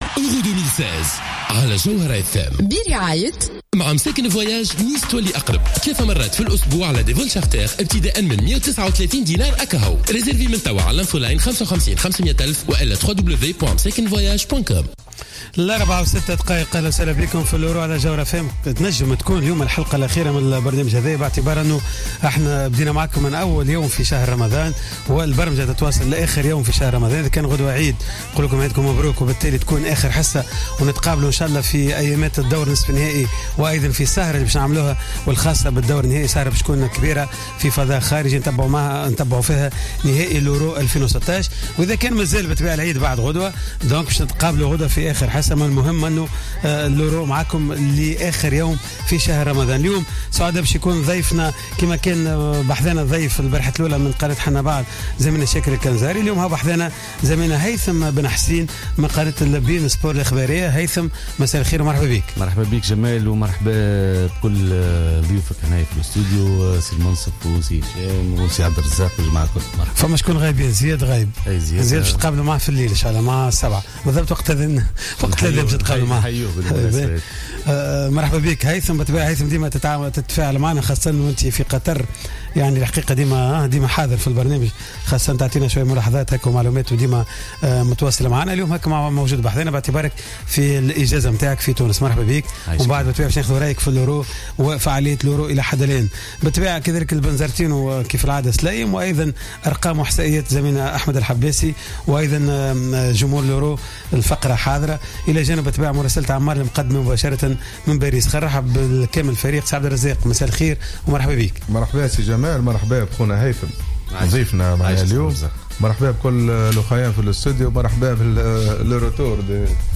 مباشرة من باريس